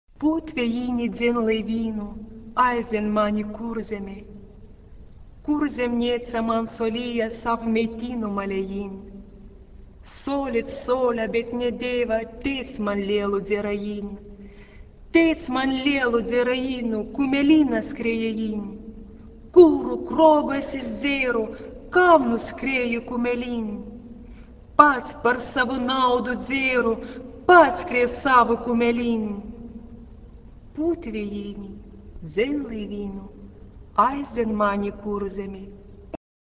Genre-Style-Form: Secular ; Popular ; Folk music
Mood of the piece: expressive ; slow
Keywords: a cappella ; boat ; girl ; beach ; wind ; blow ; sailor ; drunkard